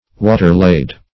Search Result for " water-laid" : The Collaborative International Dictionary of English v.0.48: Water-laid \Wa"ter-laid`\, a. Having a left-hand twist; -- said of cordage; as, a water-laid, or left-hand, rope.